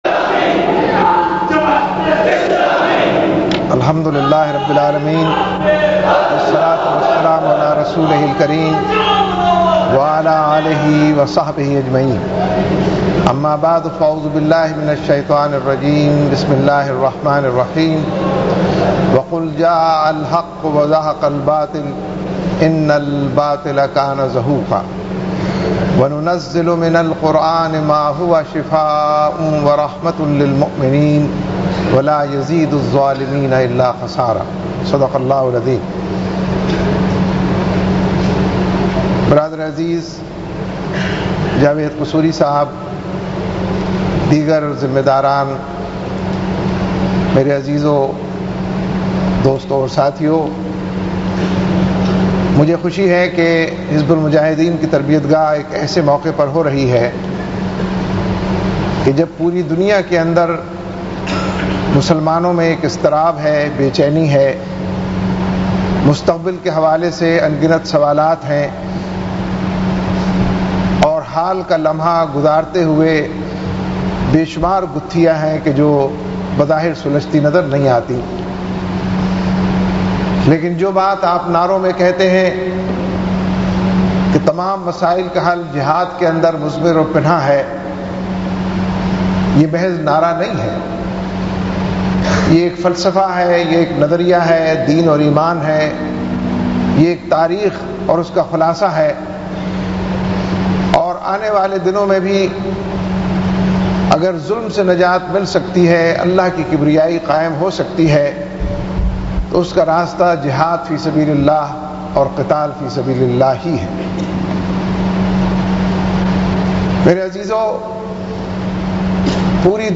Ijtama Aam at Qurtaba
4161_Ijtama-Aam-at-Qurtaba_Syed-Munawar-Hasan.mp3